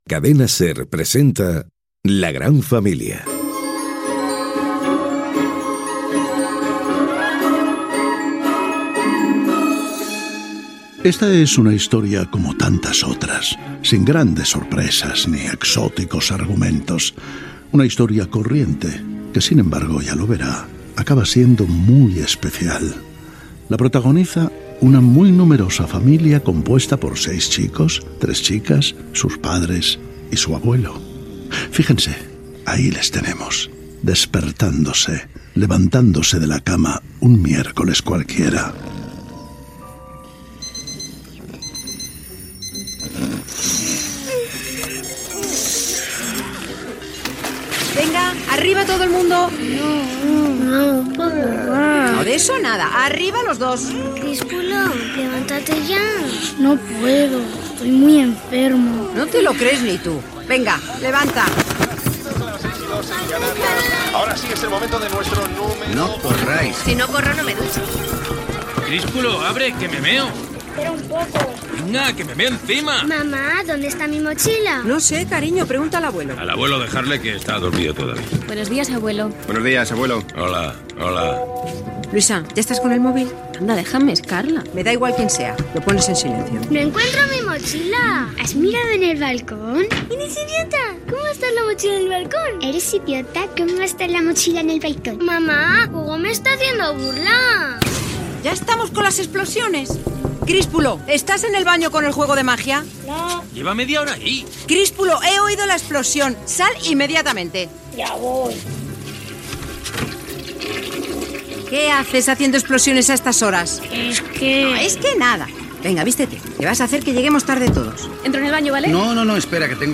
Fragment de l'emissió de l'obra "La gran familia". Indicatiu del programa, el narrador situa l'acció i primeres escenes.
Ficció